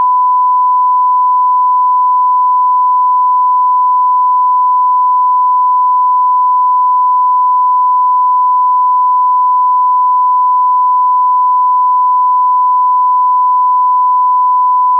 SmartAudio/44100-sine-1khz-mono-s16_le-15s.wav at fd52e99587e8f15c28df951202b45d6693bd498a